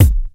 • 2000s Dance Hall Kick Single Hit A Key 31.wav
Royality free kick drum single shot tuned to the A note. Loudest frequency: 796Hz